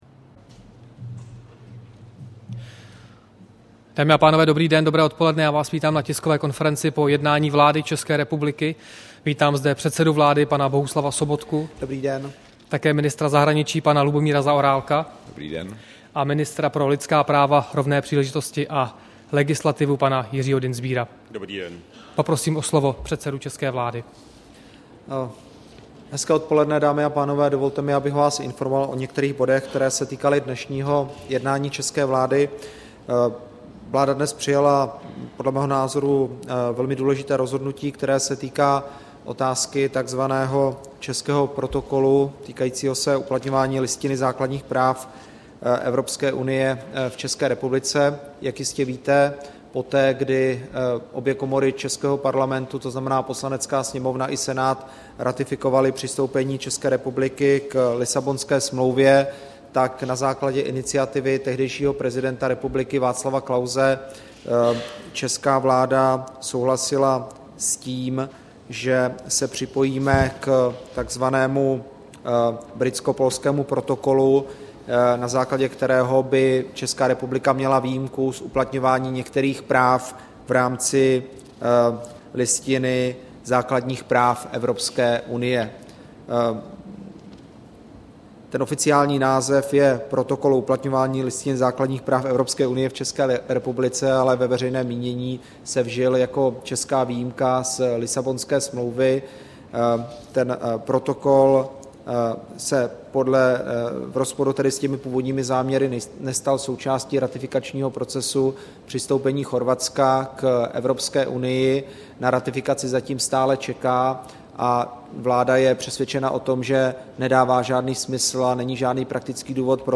Tisková konference po jednání vlády, 19. února 2014